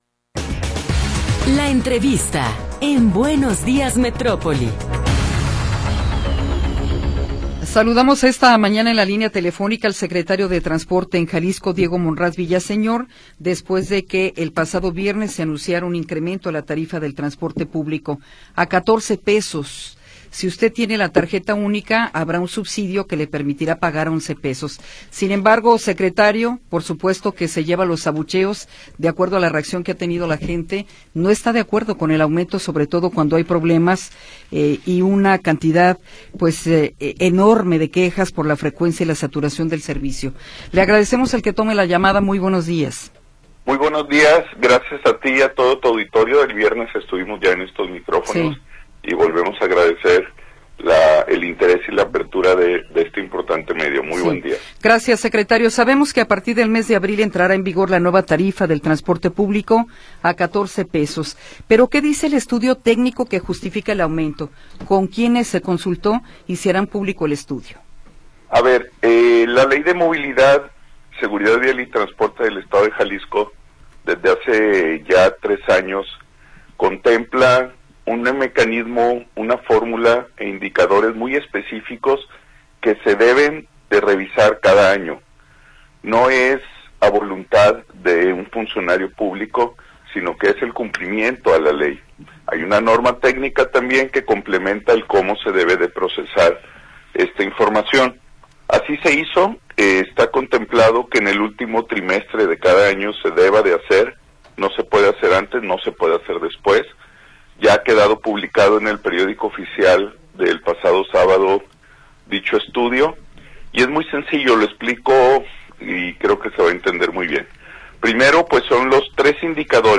Entrevista con Diego Monraz Villaseñor